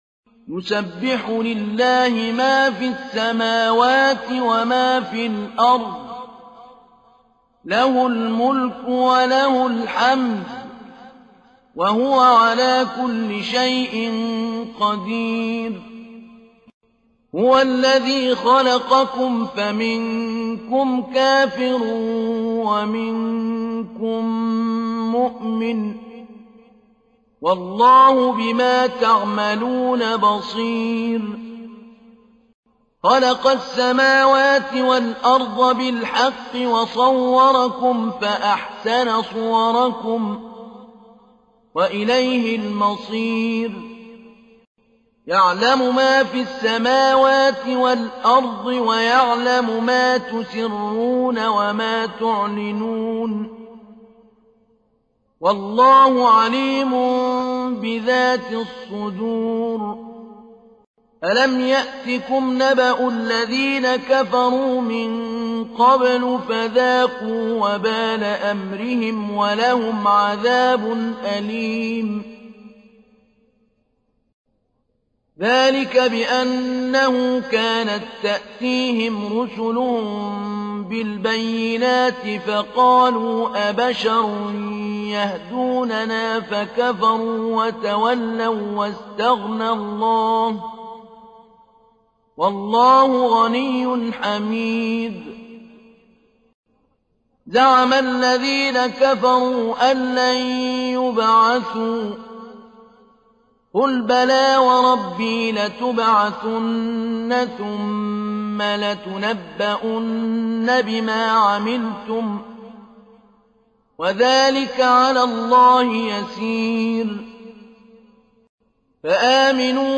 تحميل : 64. سورة التغابن / القارئ محمود علي البنا / القرآن الكريم / موقع يا حسين